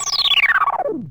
HolovidOff.wav